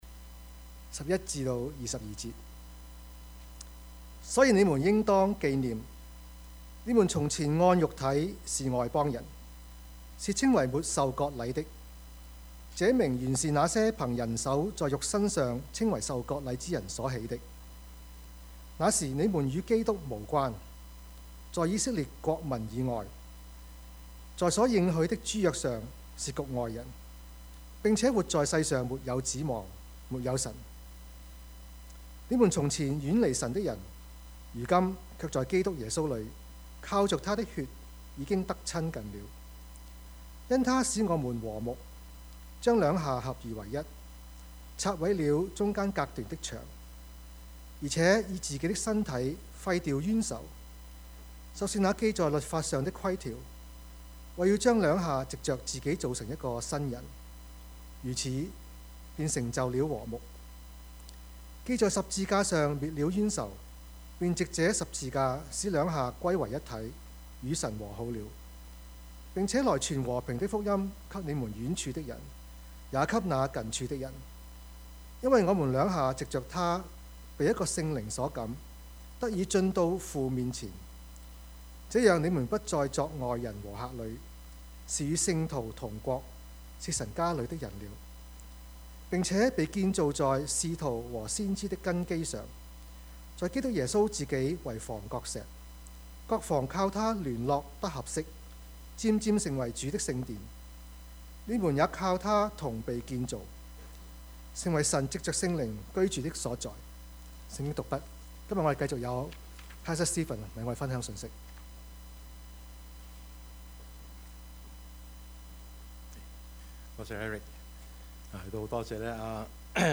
Passage: 以弗所書 2:11-22 Service Type: 主日崇拜
Topics: 主日證道 « 今時唔同往日（上） 患難與榮耀 »